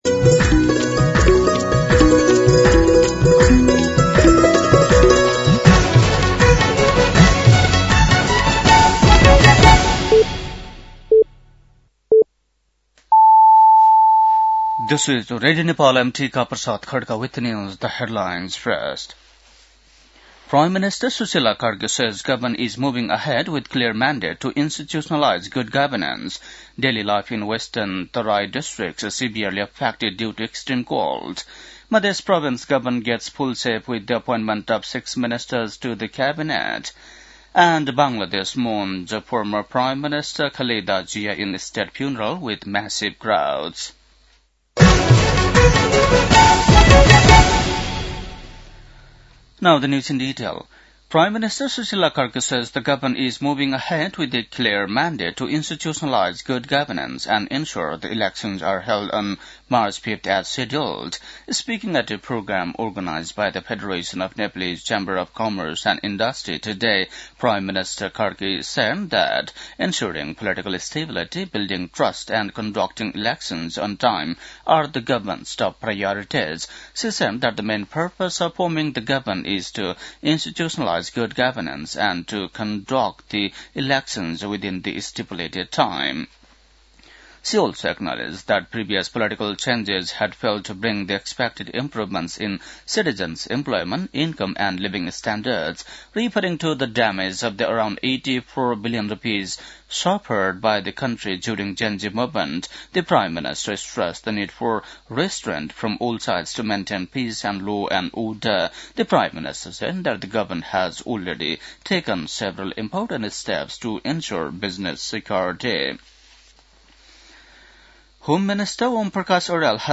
बेलुकी ८ बजेको अङ्ग्रेजी समाचार : १६ पुष , २०८२
8-pm-NEWS-09-16.mp3